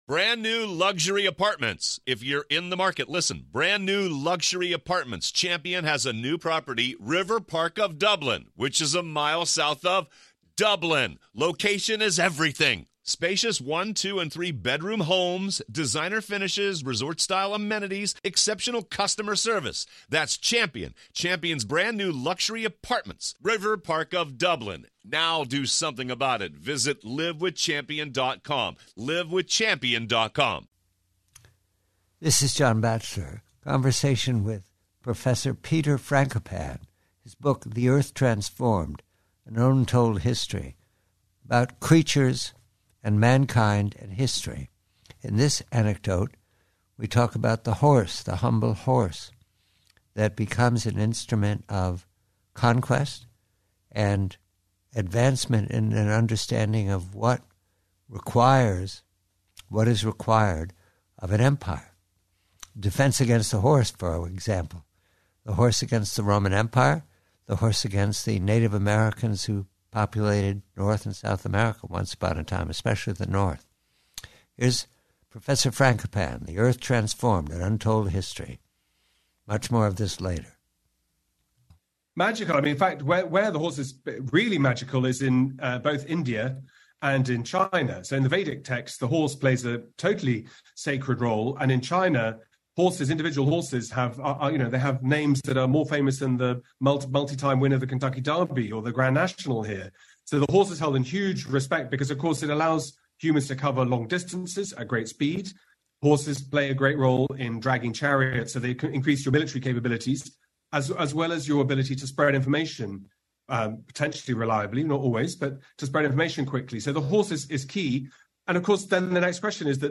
PREVIEW: Conversation with Professor Peter Frankopan, author of "The Earth Transformed," regarding the humble horse out of Asia as a tool for conquest and sophistication.